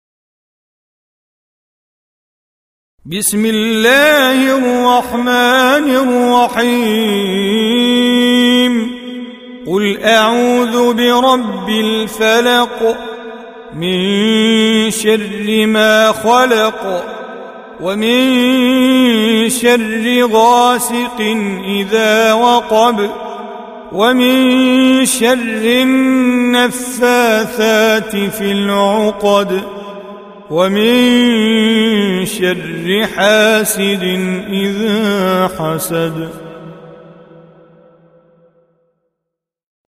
113. Surah Al-Falaq سورة الفلق Audio Quran Tajweed Recitation
Surah Repeating تكرار السورة Download Surah حمّل السورة Reciting Mujawwadah Audio for 113.